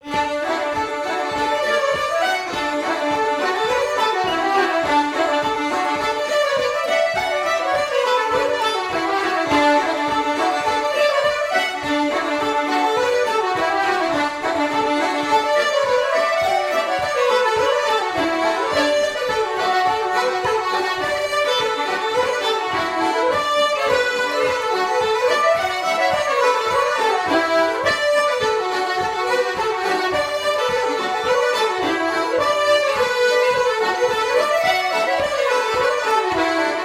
Irish traditional music